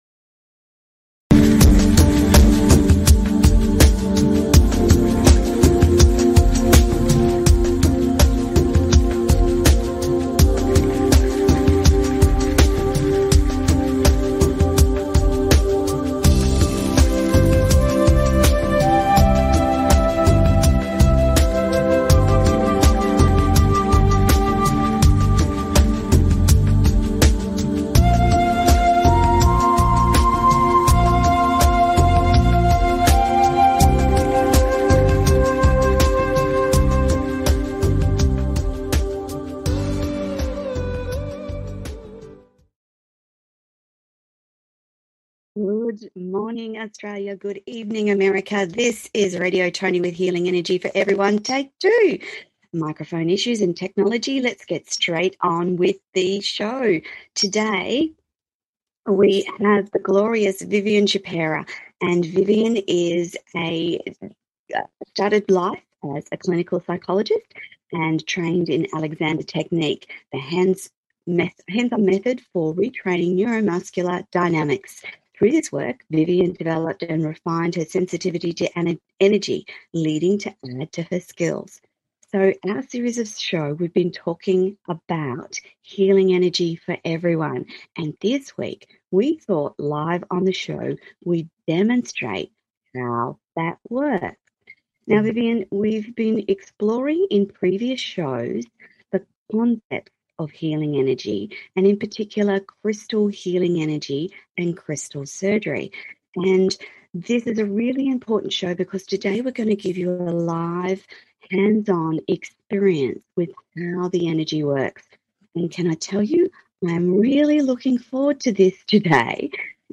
Live demonstration of using healing energy and in particular crystal healing energy and crystal surgery